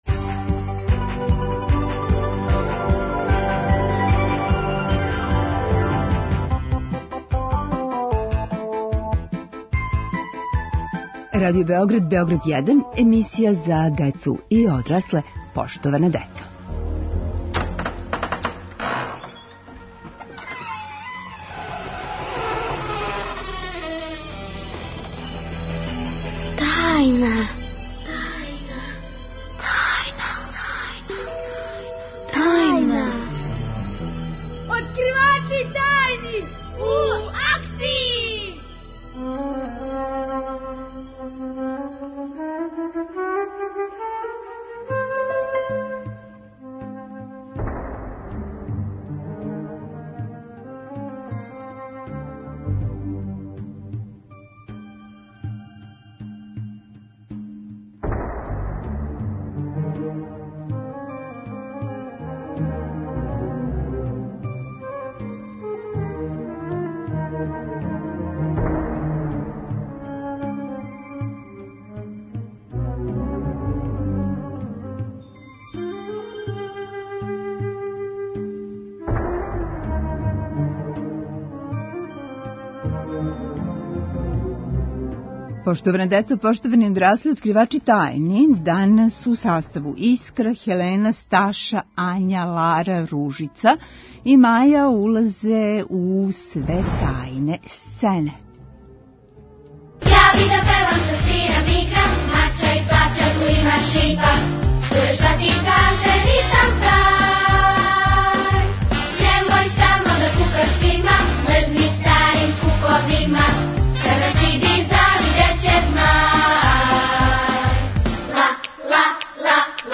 Откривамо тајне сцене. У томе нам помажу: застолог, зоотајнолог, шумски дописник…